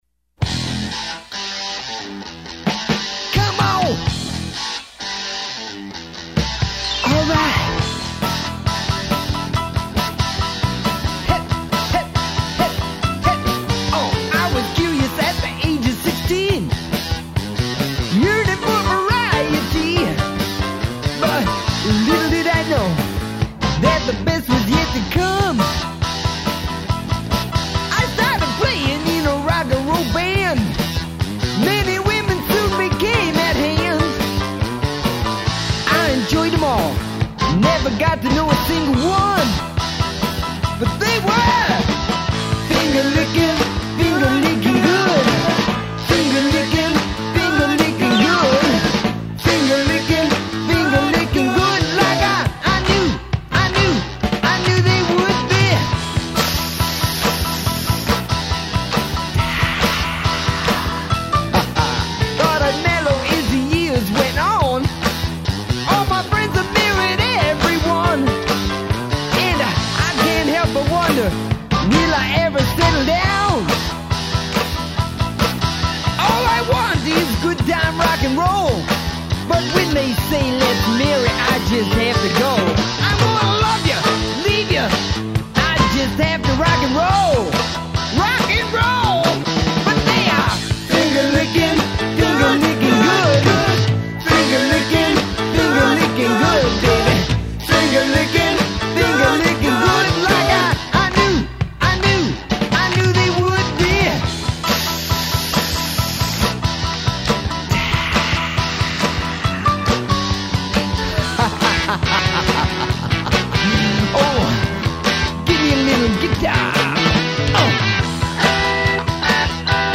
Rhythm guitar
Drummer
Bass